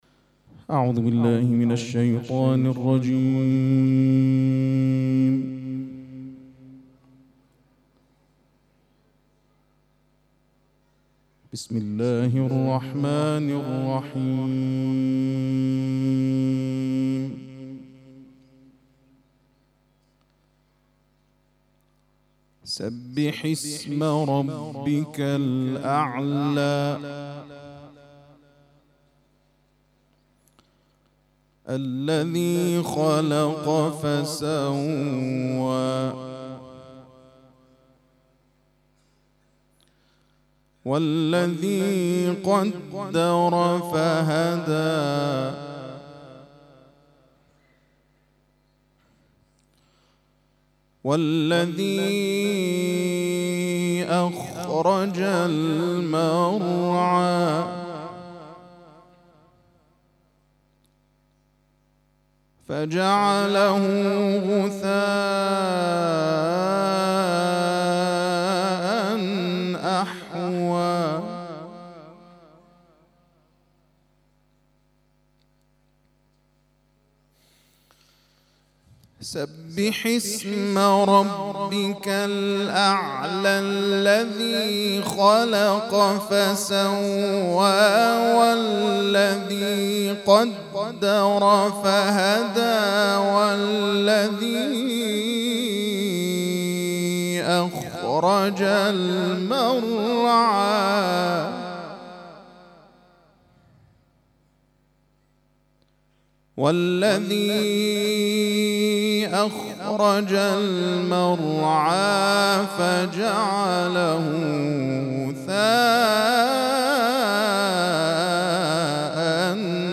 تلاوت ظهر
تلاوت قرآن کریم